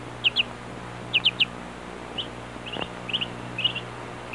Baby Birds Sound Effect
Download a high-quality baby birds sound effect.
baby-birds-1.mp3